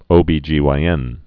(ōbē-jēwī-ĕn)